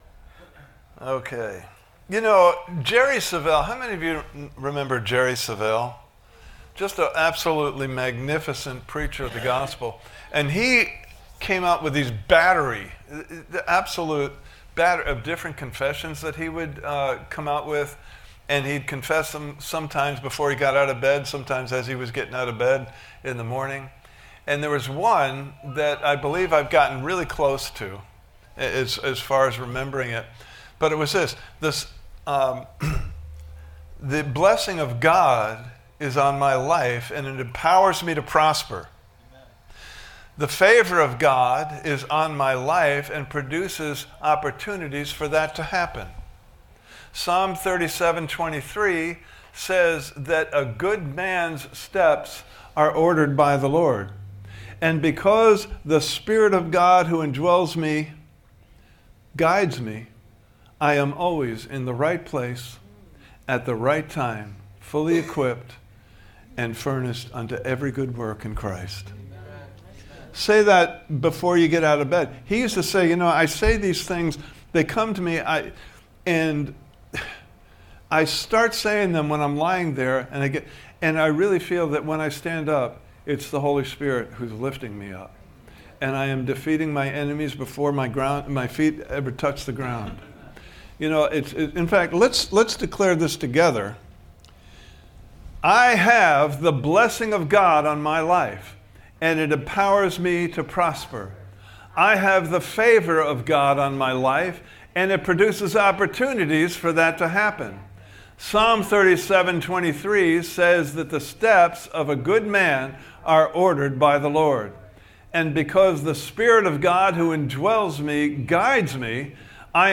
Service Type: Sunday Morning Service « Part 1: The Joy of the Lord is Our Victory!